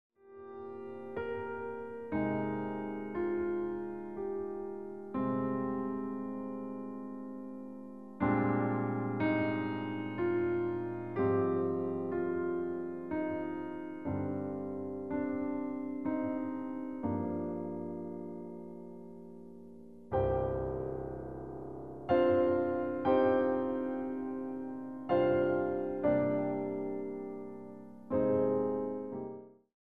Long tracks of meditative music for stretch classes